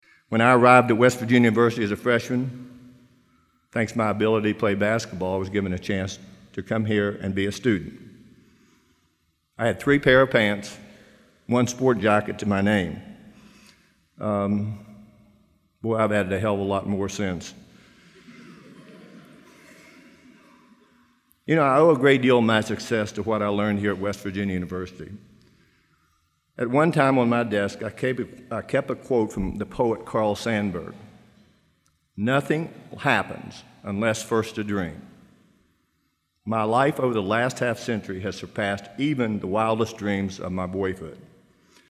Become “fighters” rather than “floaters” or “flee-ers,” NBA great Jerry West urged graduates of WVU’s College of Physical Activity and Sports Sciences Sunday (May 16), saying passive personas greatly inhibit professional and personal growth.